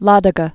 (lädə-gə), Lake